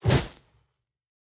Light Torch 2.ogg